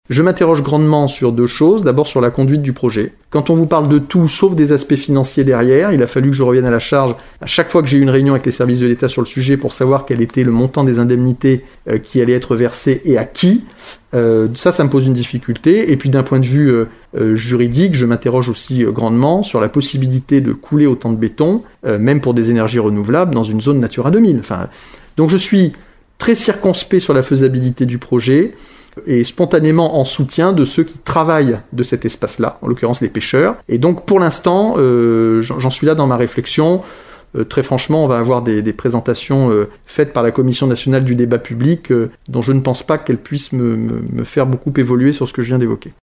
Mickaël Vallet a présenté ses vœux à la presse ce matin depuis sa permanence à Marennes.